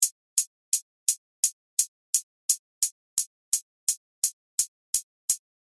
And, as you can see on the Gain knob, I’ve boosted the signal to 12 dB, so you can hear the Saturation effect.
With this example, I’ve chosen to just solo the HiHats, as you could hear the effect better.
The first half is without the Saturation, and the second with it.
HiHat_Tutorial_Loop.mp3